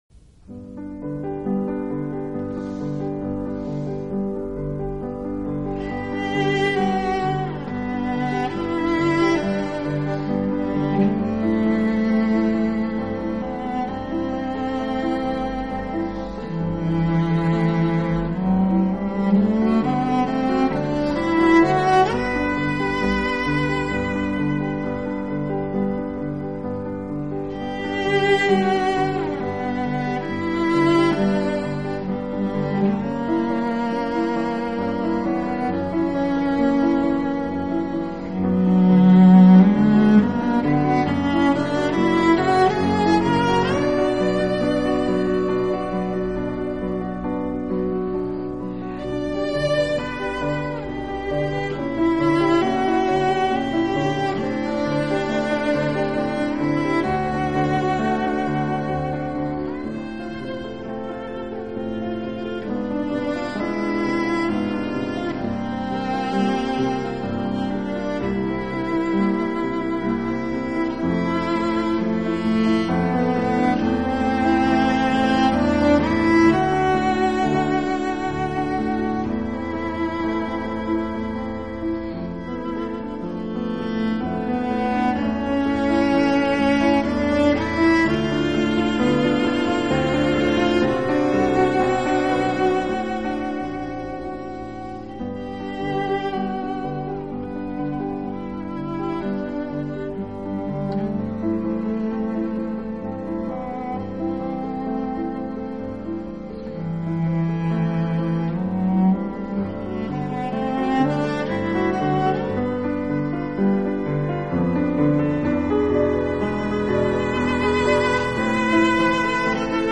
【大提琴专辑】
这张CD在重播时充满细致入微的音乐感，洋溢出春风化雨般的暖意。
于那清澄透明的质感，丰富的空间残响和精锐的结像力，则使重播效果如浮雕一般立体鲜明，
闭上眼，连演奏者的呼吸和手势都能听到、看到。